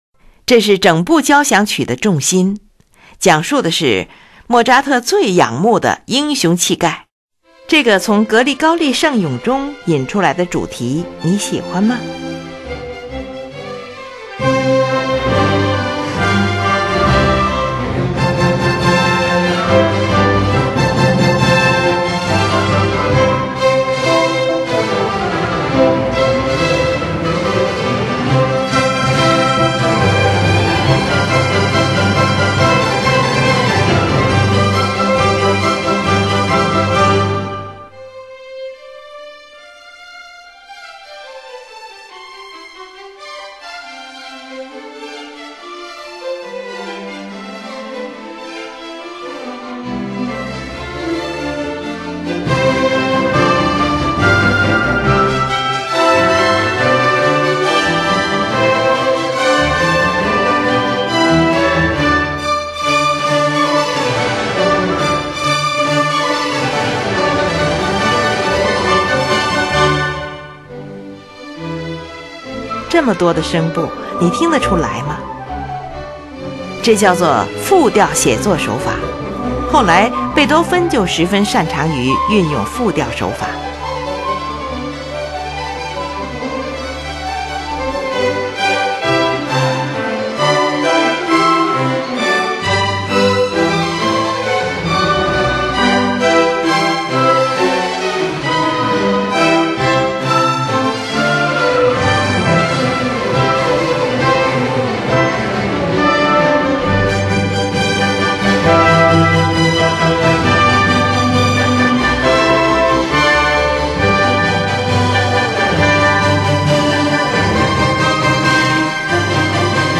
Molto allegro 十分的快板
这么多个声部，你听明白了吗？这叫做复调写作手法。
复调，三重赋格曲。
第四乐章，这是整部交响曲的中心。